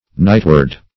Nightward \Night"ward\, a. Approaching toward night.